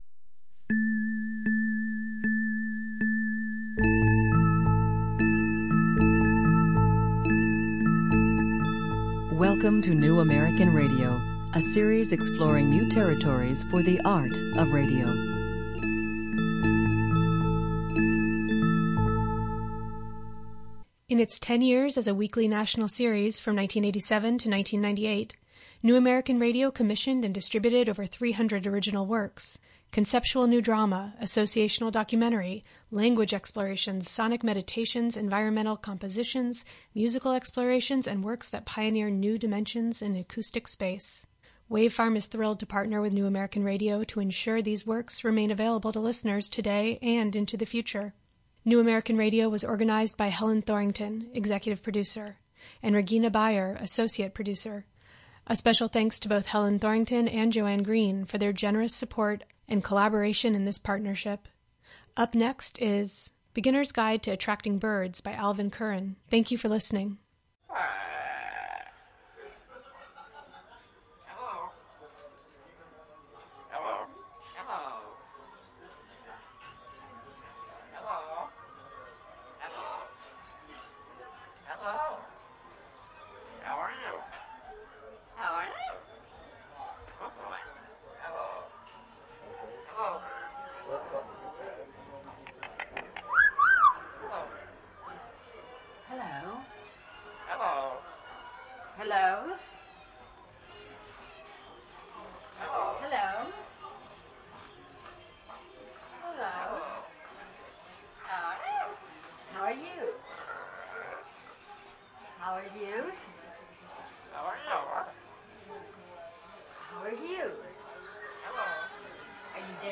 A tone-poem for radio
the magical singing tones of high-tension wires in the wind